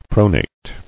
[pro·nate]